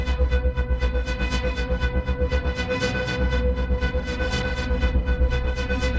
Index of /musicradar/dystopian-drone-samples/Tempo Loops/120bpm
DD_TempoDroneB_120-B.wav